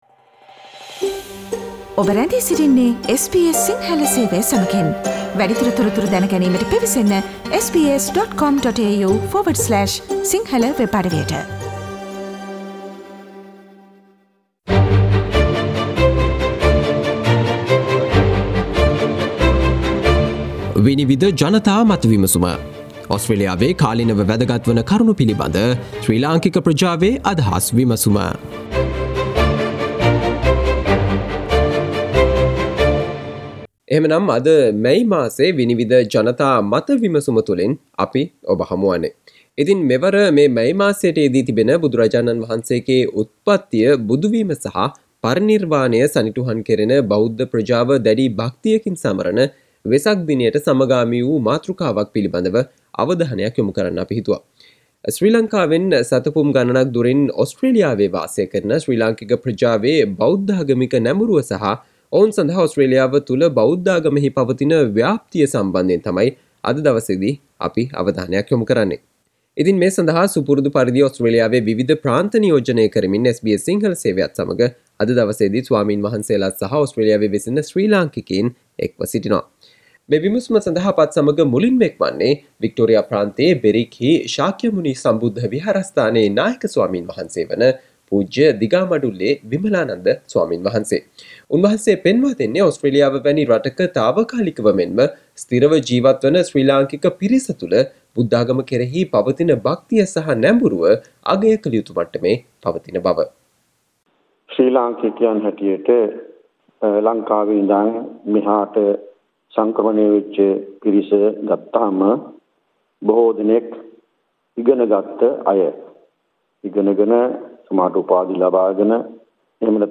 SBS Sinhala Vinivida Monthly panel discussion on How close you can get to Buddhism in Australia
Listen to ideas from the members of Sri Lankan community in Australia talking about The Buddhist orientation of the Sri Lankan community living in Australia and the spread of Buddhism in Australia as a religion.